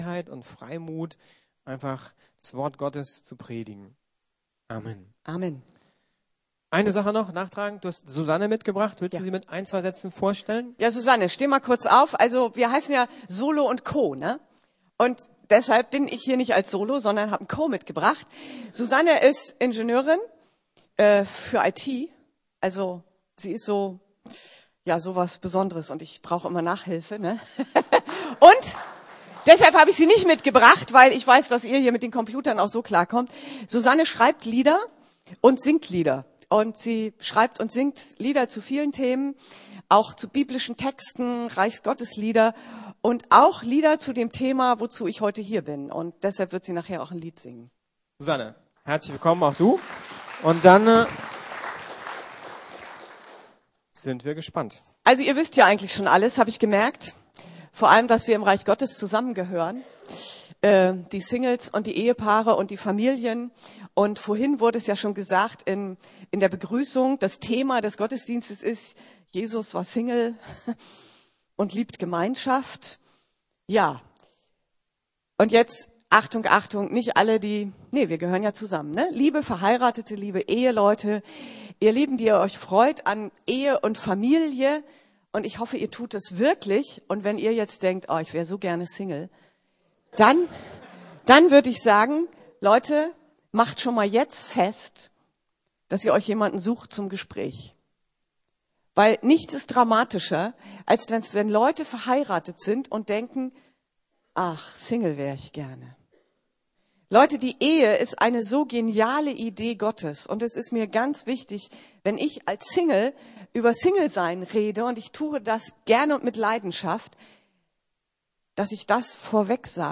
Jesus war single - und liebt Gemeinschaft ~ Predigten der LUKAS GEMEINDE Podcast